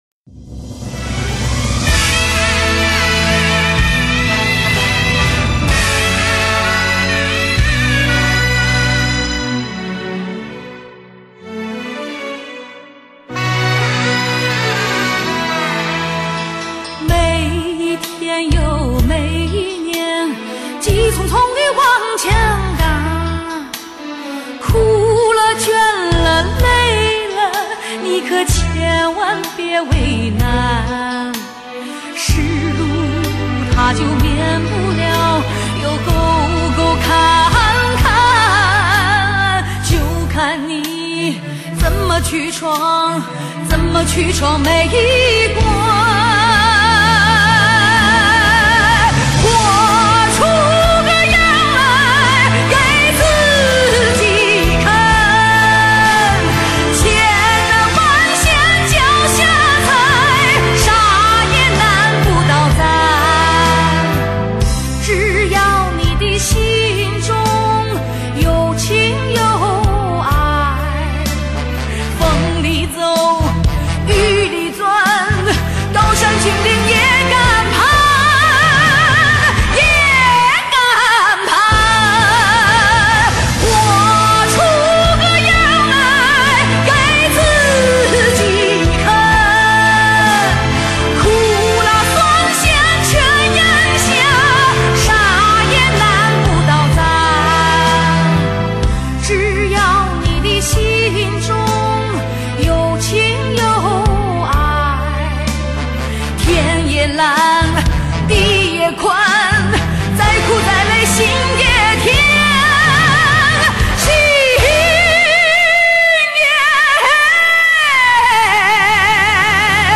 ■她的外表清新端庄，既有东北人的落落大方而又不失女性的柔美；她的歌声大气辽阔,富有磁性而又不失细腻。
片尾曲